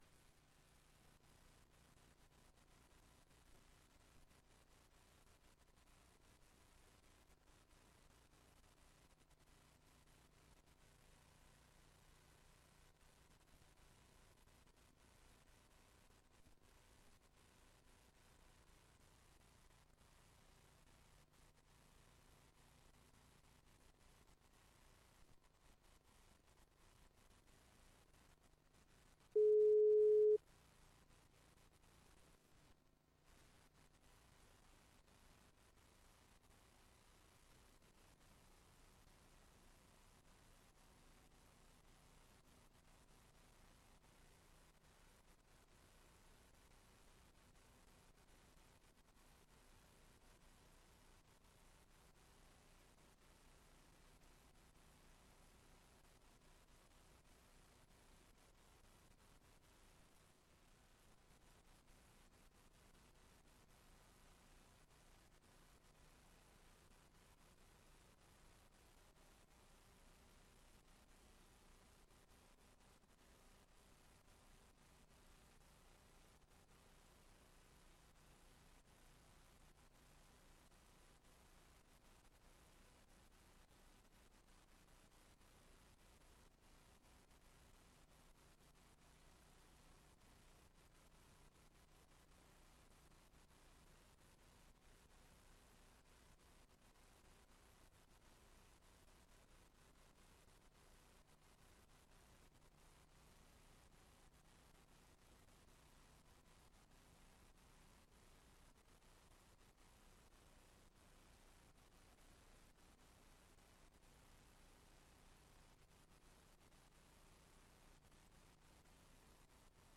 Raadsbijeenkomst 25 maart 2025 19:30:00, Gemeente Tynaarlo
Locatie: Raadszaal